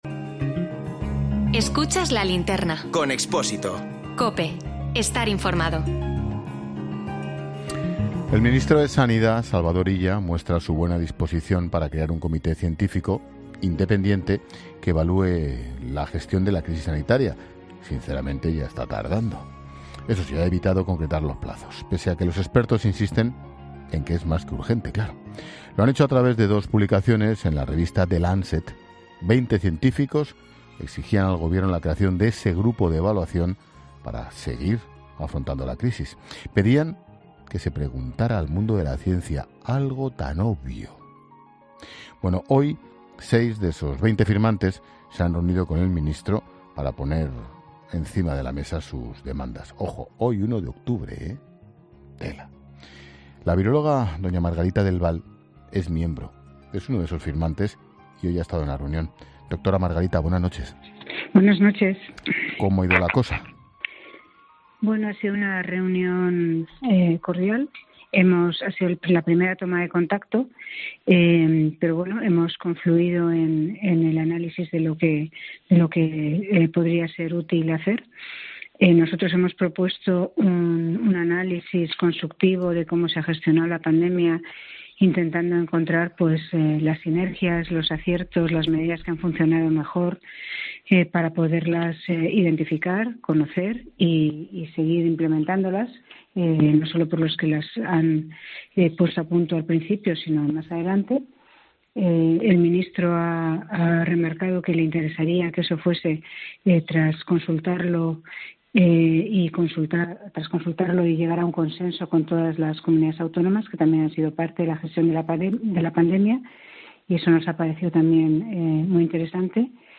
Margarita del Val, en COPE: Hemos propuesto al ministro un análisis sobre cómo se ha gestionado la pandemia